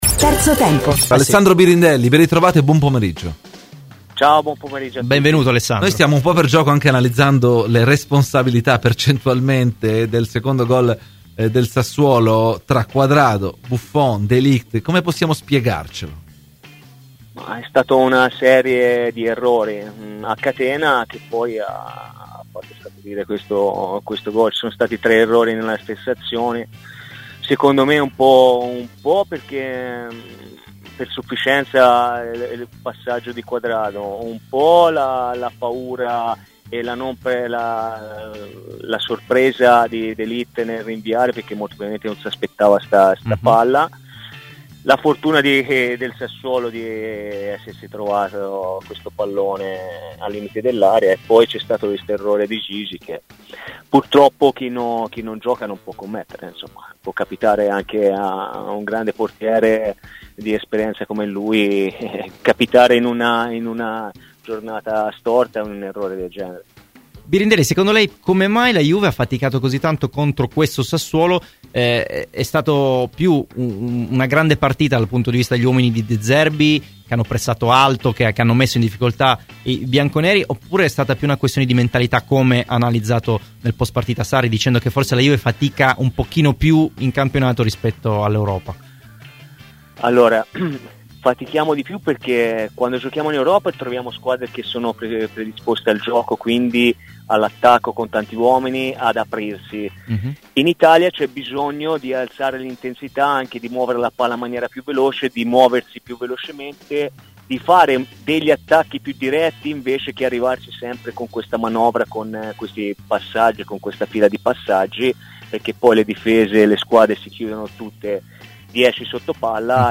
L’ex terzino della Juventus Alessandro Birindelli è intervenuto a Radio Bianconera nel corso di ‘Terzo Tempo’: “Il gol di Caputo? Una serie di errori che ha portato a subire questo gol. Un po’ di sufficienza di Cuadrado, un po’ di paura e sorpresa di De Ligt, la fortuna del Sassuolo e l’errore di Buffon, solo chi non gioca non ne fa”.